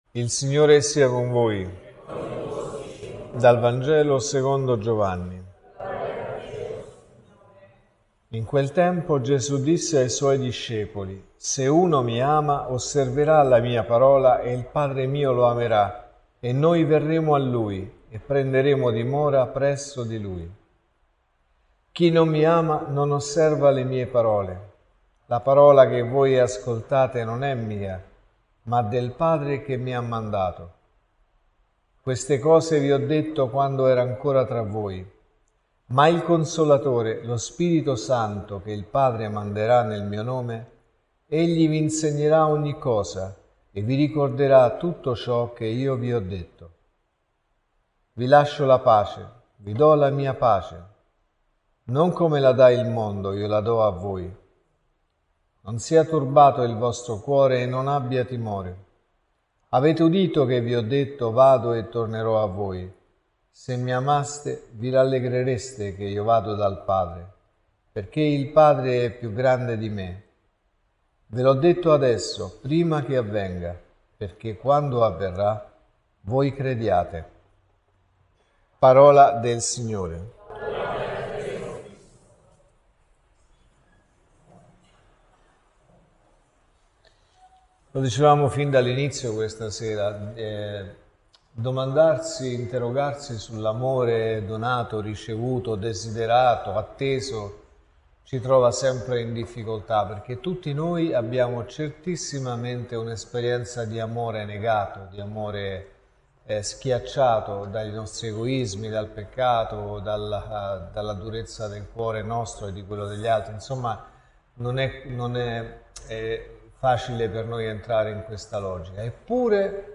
Lo Spirito Santo vi ricorderà tutto ciò che io vi ho detto.(Messa del mattino e della sera)